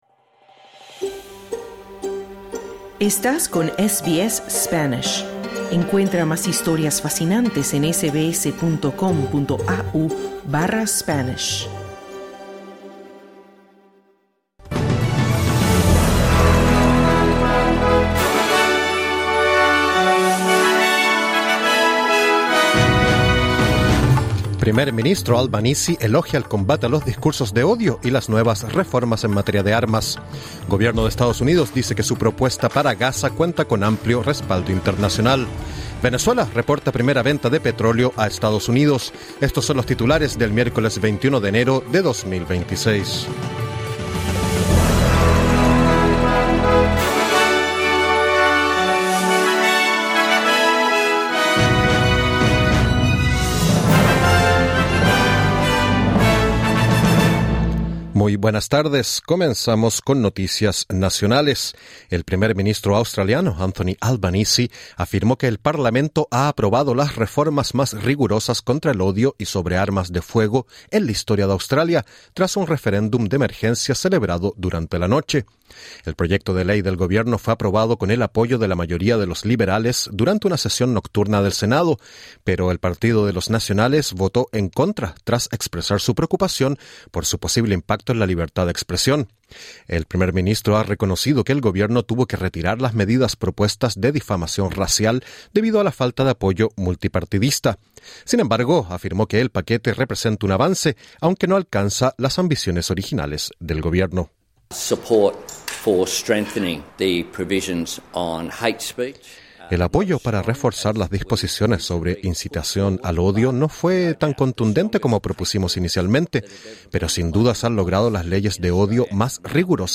Boletín 21/01/26: El primer ministro australiano afirmó que el Parlamento ha aprobado las reformas más rigurosas contra el odio y sobre armas de fuego en la historia de Australia. Por otra parte, gobierno estadounidense dice que su plan para Gaza cuenta con amplio respaldo internacional, y Venezuela recibe primeros ingresos por venta de petróleo a EE.UU.